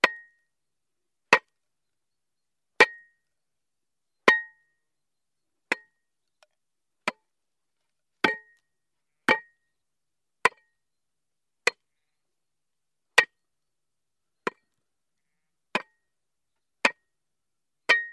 Descarga de Sonidos mp3 Gratis: pico pala.
pickaxe-effects.mp3